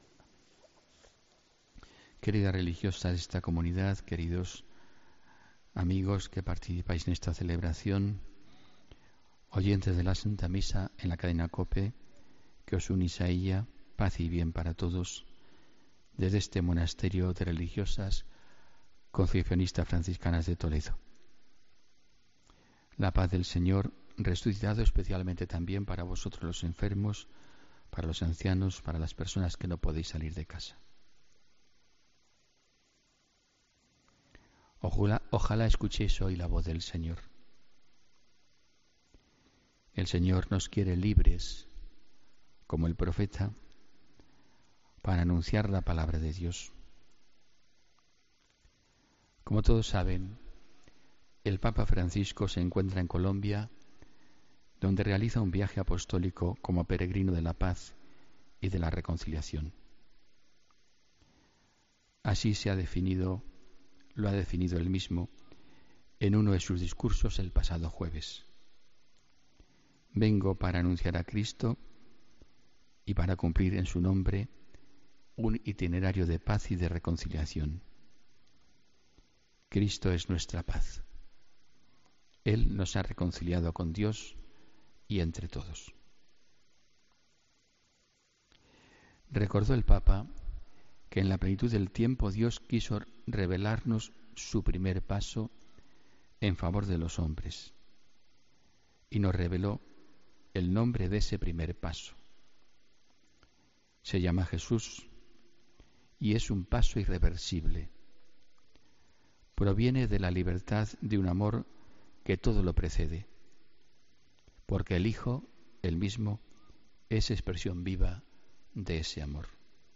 AUDIO: Homilía del 10 de septiembre de 2017